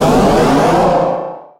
Cri de Méga-Tortank dans Pokémon HOME.
Cri_0009_Méga_HOME.ogg